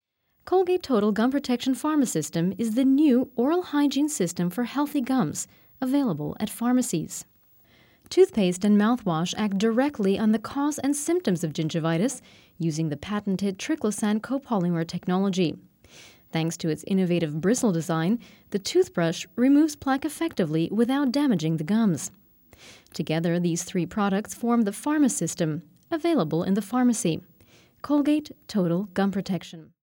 englisch (us)
Sprechprobe: Werbung (Muttersprache):